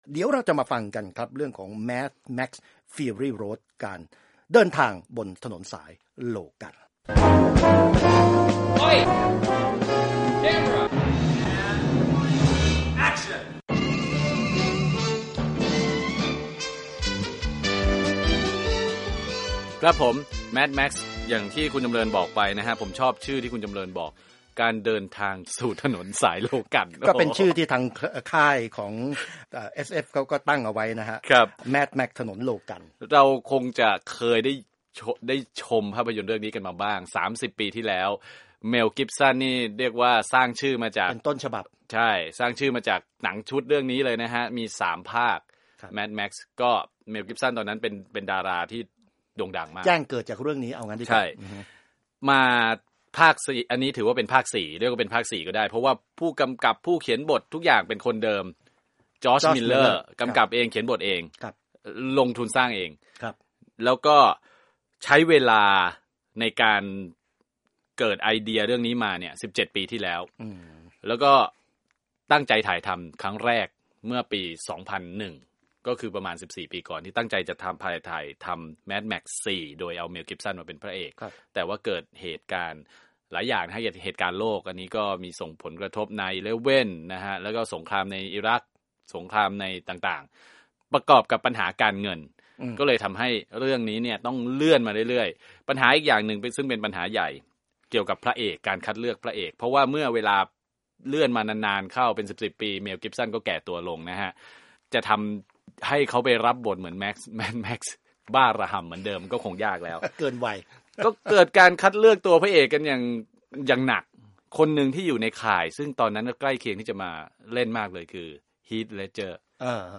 วิจารณ์ภาพยนตร์